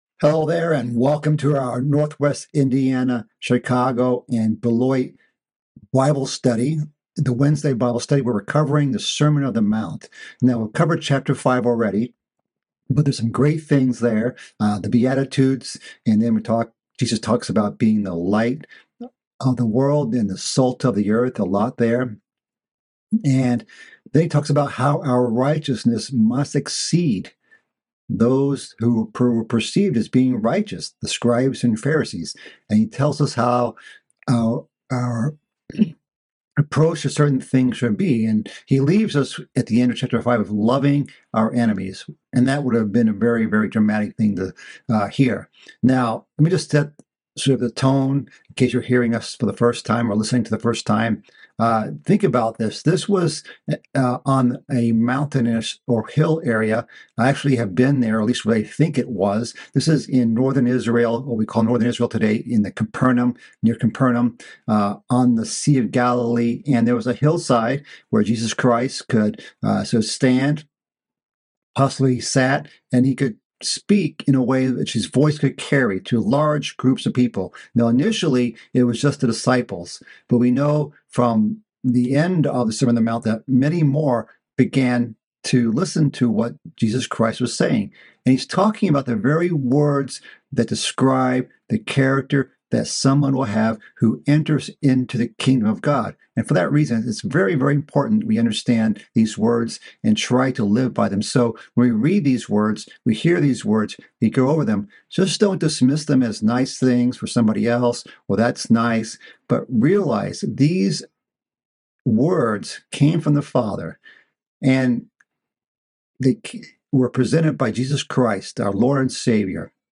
This is part of a mid-week Bible study series covering the sermon on the mount. This study delves further into the section on how to pray.